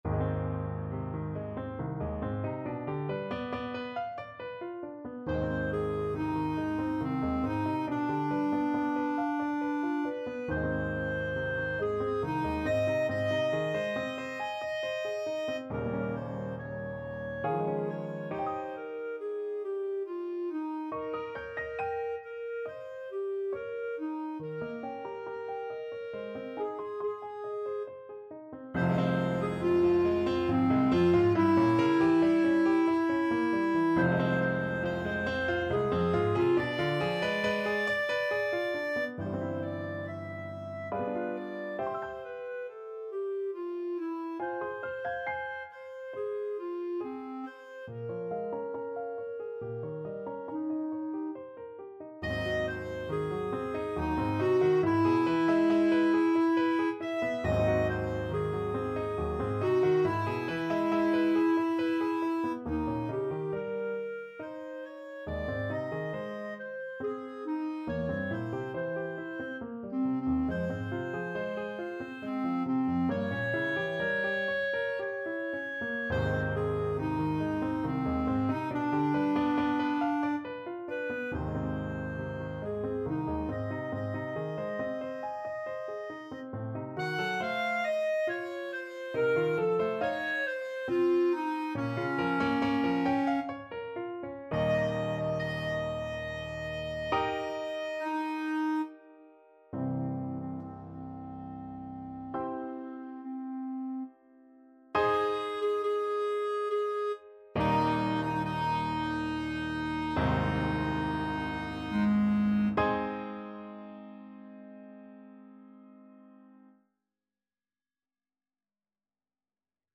Clarinet
Ab major (Sounding Pitch) Bb major (Clarinet in Bb) (View more Ab major Music for Clarinet )
3/4 (View more 3/4 Music)
~ = 69 Large, soutenu
Classical (View more Classical Clarinet Music)